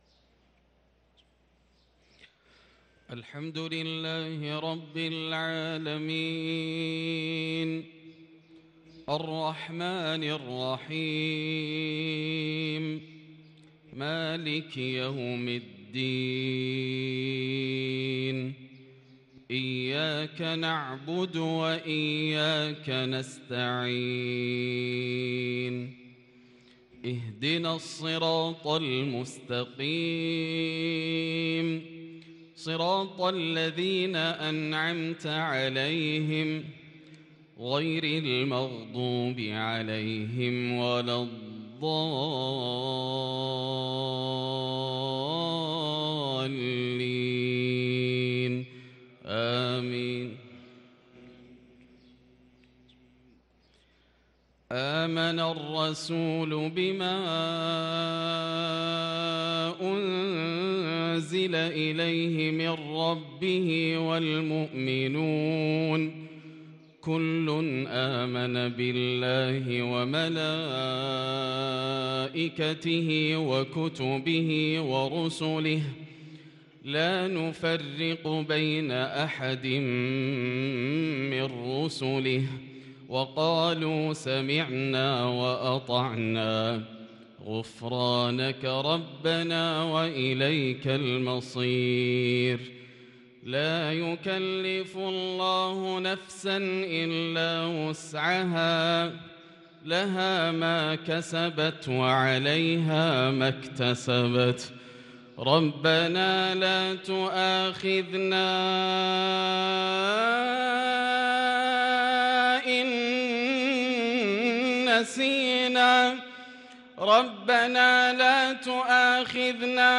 تلاوة تفوق الوصف من سورتي البقرة و آل عمران - مغرب الجمعة 1-5-1444هـ > عام 1444 > الفروض - تلاوات ياسر الدوسري